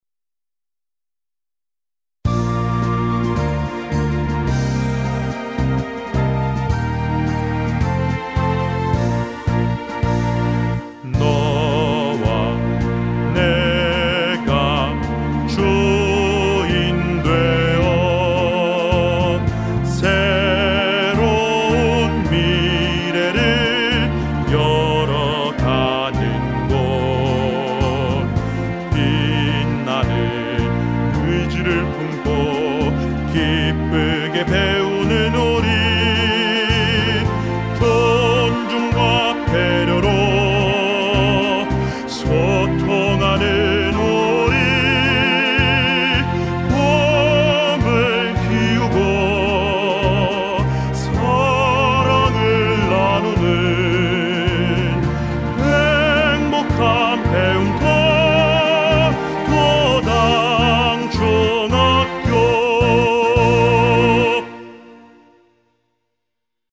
도당중학교 교가